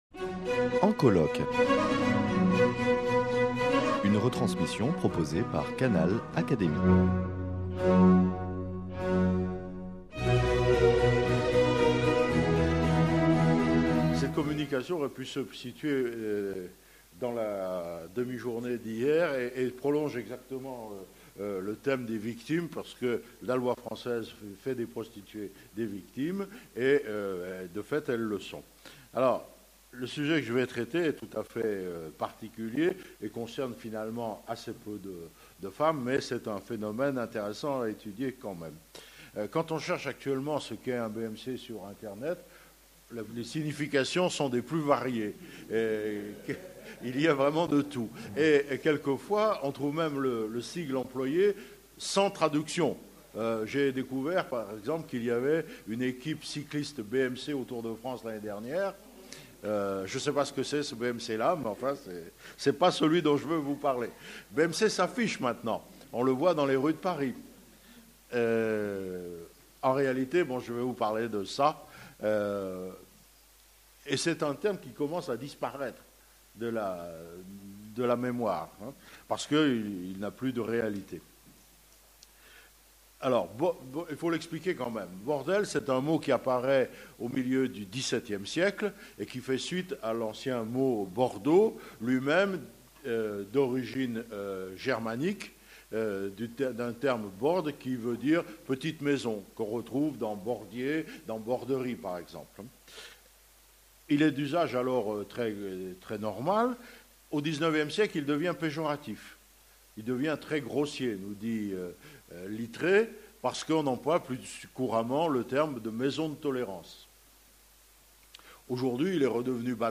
lors des journées d’étude « La guerre et les femmes »